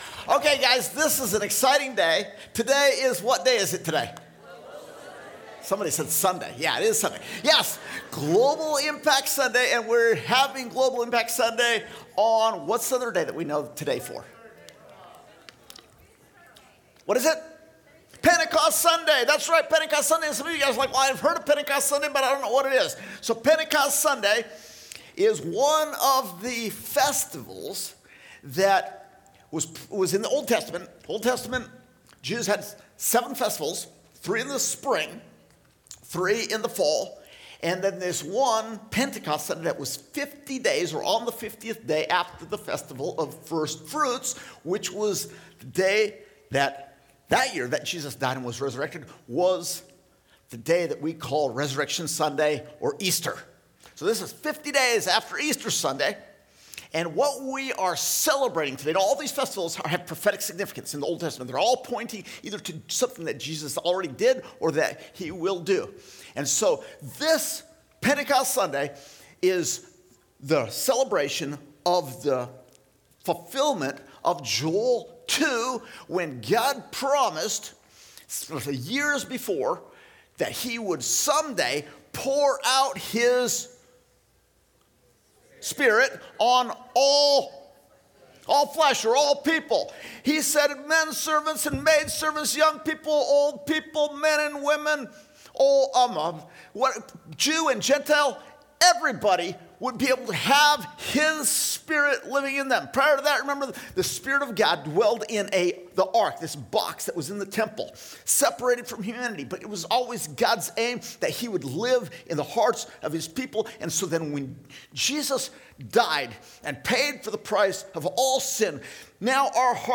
6.8.25_SecondService_Sermon.mp3